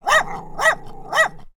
Odgłosy zwierząt wiejskich
Pies
animals_dogs_x2_barking_small_004.mp3